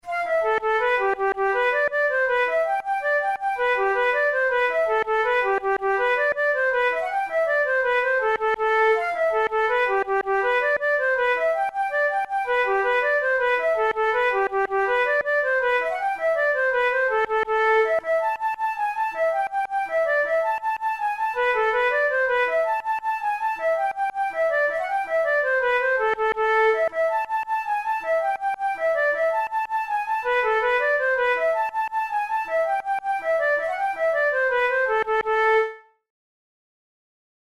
InstrumentationFlute solo
KeyA minor
Time signature6/8
Tempo108 BPM
Jigs, Traditional/Folk
Traditional Irish jig